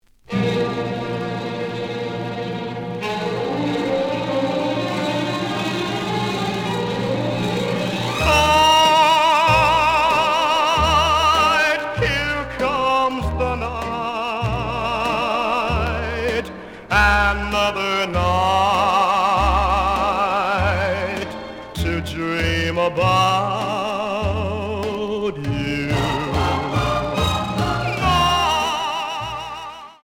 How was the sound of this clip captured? The audio sample is recorded from the actual item. Slight damage on both side labels. Plays good.)